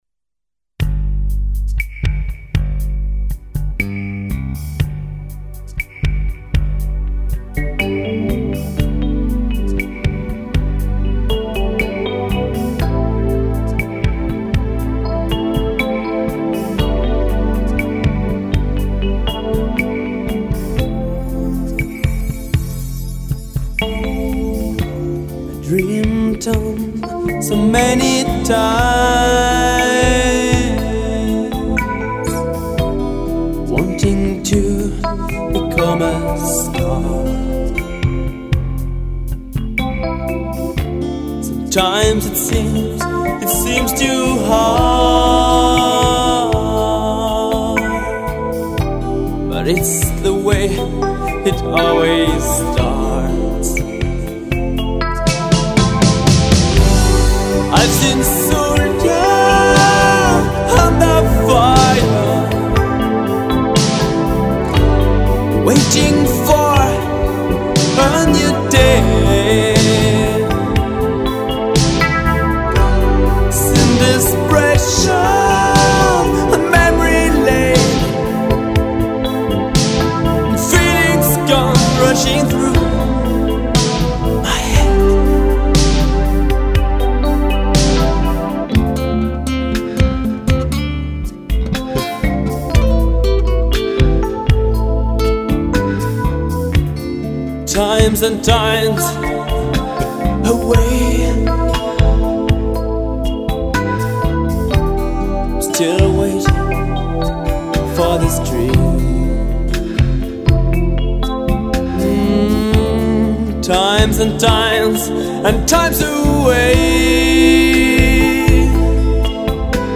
guitars
Keyboards
Drum Programming